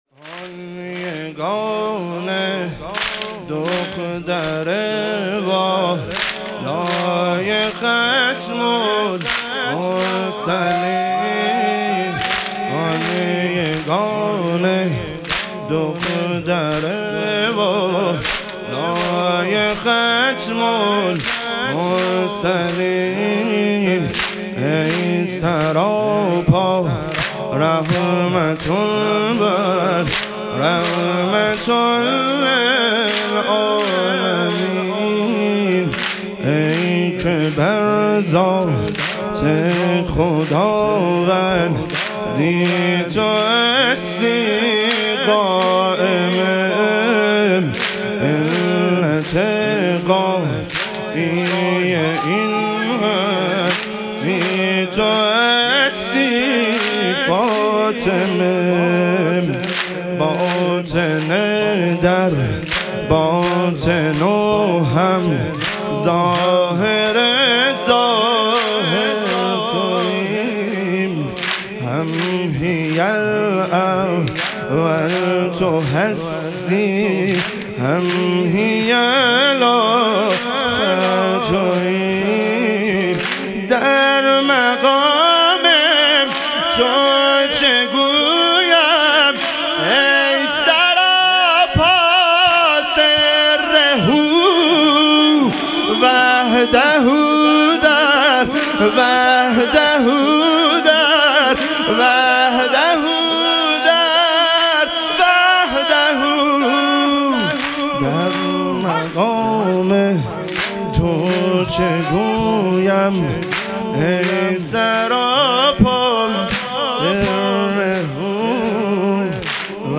ایام فاطمیه دوم - واحد